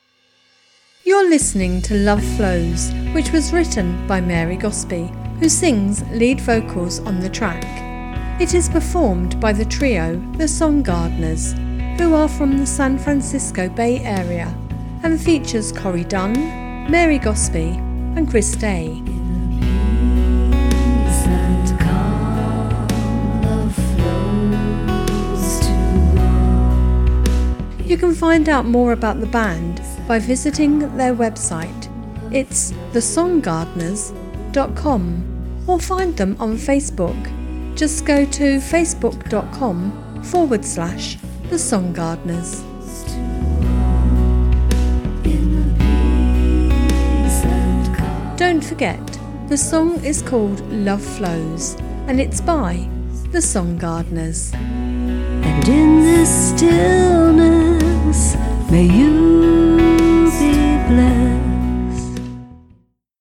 lead vocals, guitar, flute
bass
is a genuine soft pop song with broad appeal.